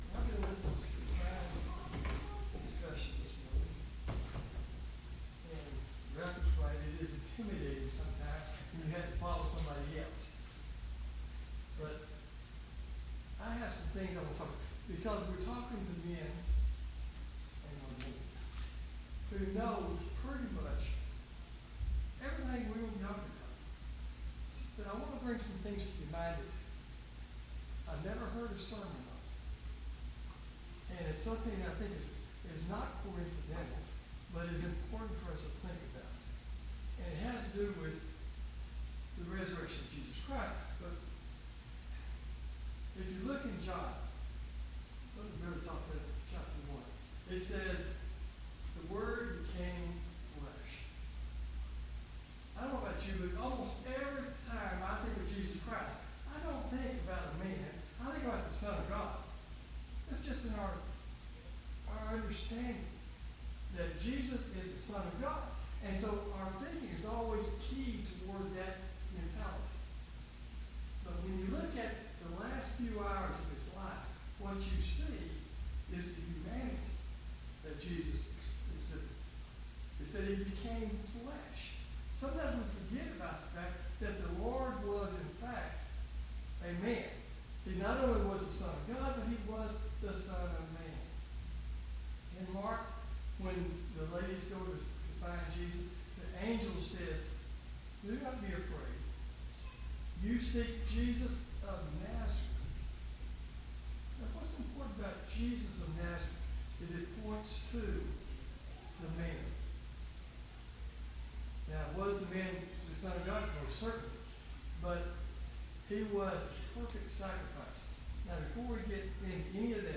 18 Service Type: VBS Adult Class « Lesson 3